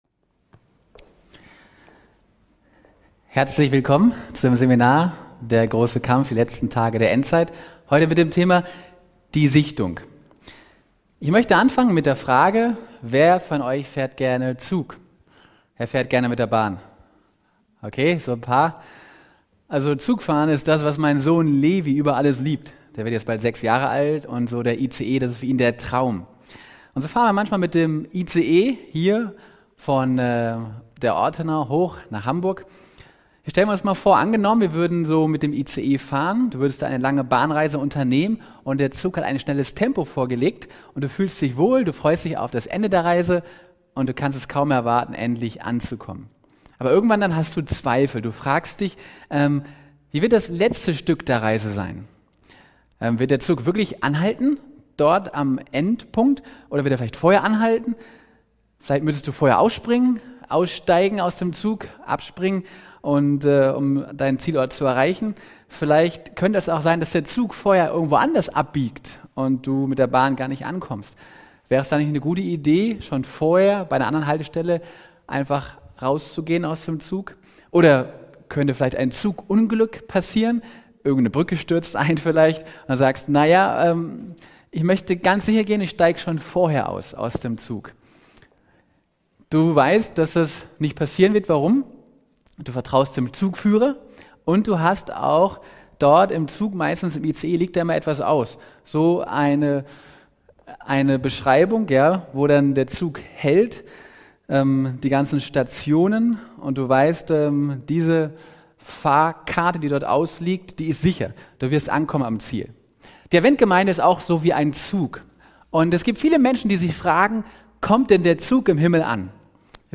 Erleben Sie eine fesselnde Erkundung prophetischer Themen und biblischer Wahrheiten, die die Adventgemeinde in den letzten Tagen betreffen. Der Vortrag entfaltet zentrale Fragen zu Treue, Irrlehren und der kommenden Sichtung.